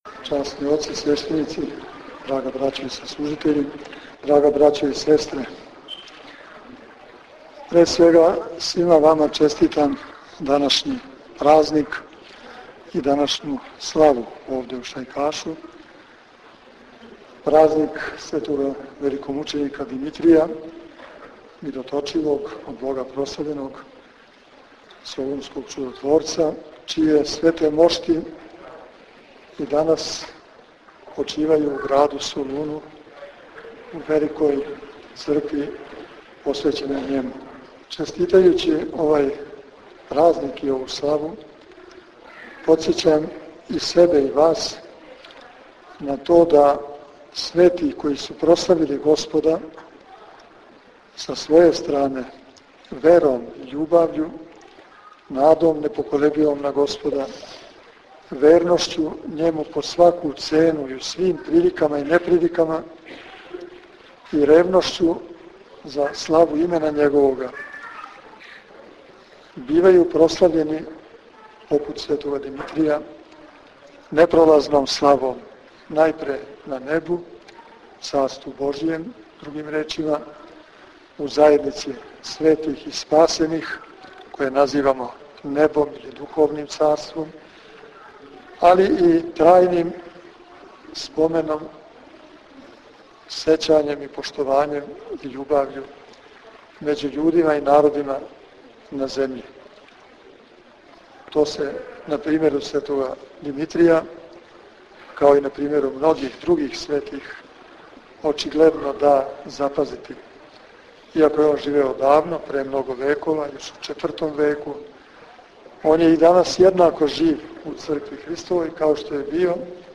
Шајкаш - Прослава храмовне славе у Шајкашу била је испуњена великом духовном радошћу.
Свети храм је био мали да прими све вернике којих је било преко пет стотина, те су многи остали испред цркве, али молитвено сједињени са онима унутра.